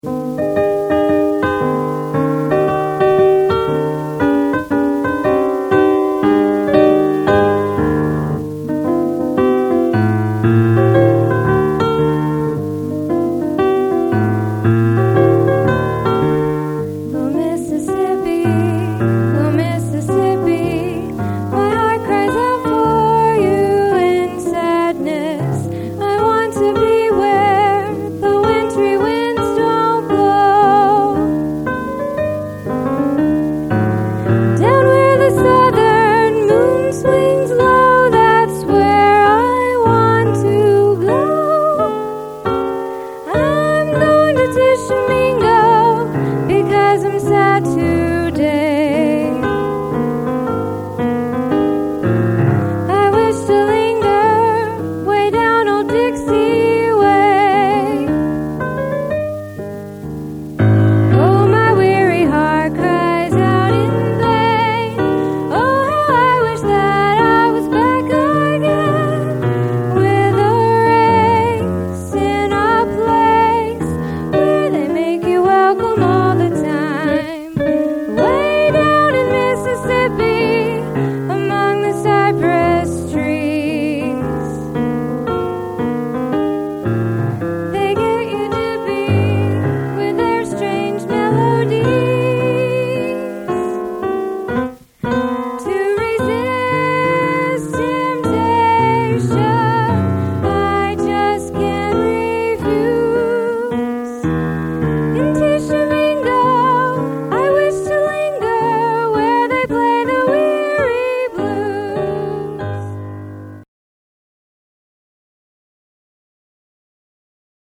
pianist
vocalist
in jazz style just emerging at the end of the ragtime era